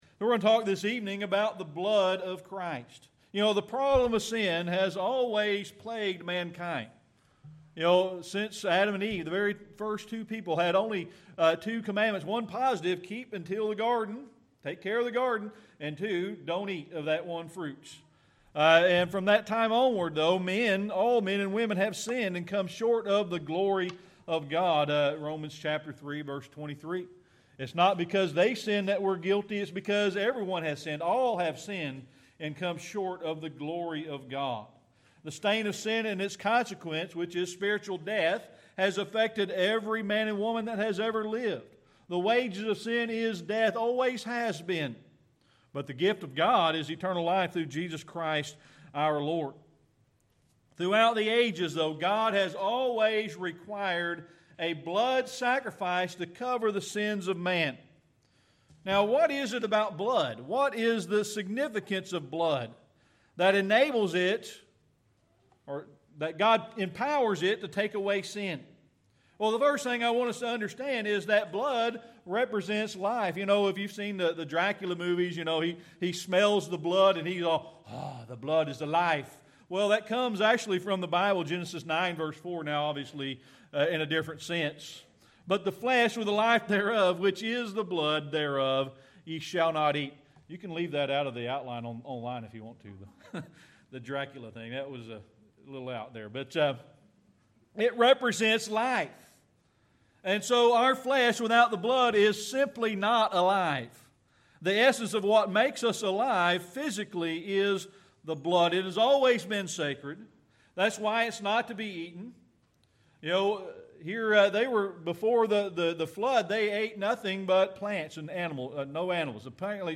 Series: Sermon Archives
Romans 3:23 Service Type: Sunday Evening Worship We're going to talk this evening about The blood of Christ.